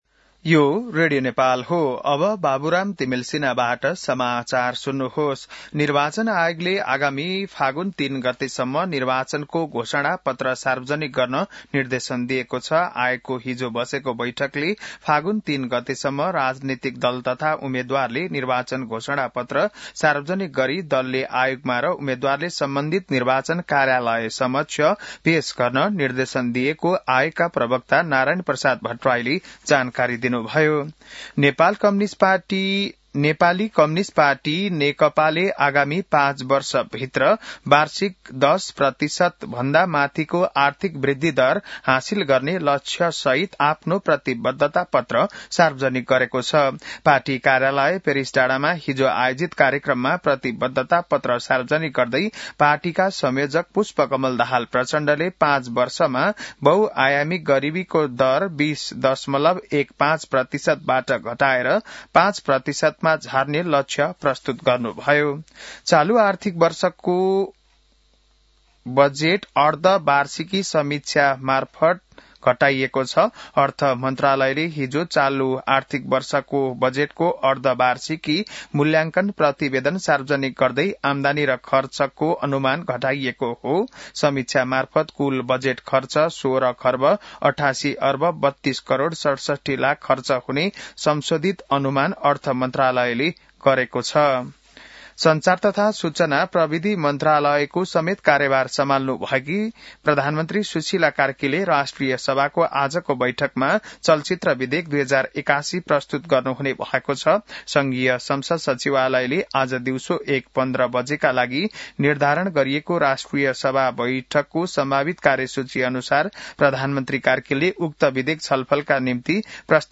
An online outlet of Nepal's national radio broadcaster
बिहान १० बजेको नेपाली समाचार : २८ माघ , २०८२